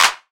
Claps
METRO-CLAP.wav